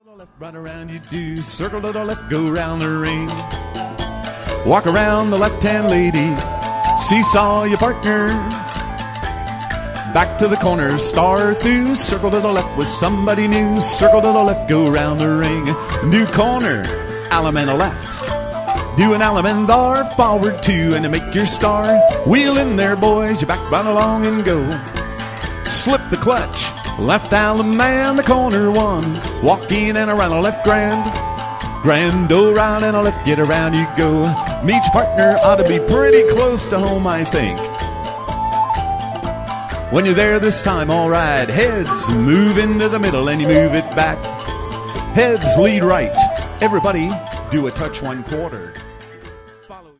Category: Patter Tag: Called Plus